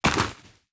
Dirt footsteps 6.wav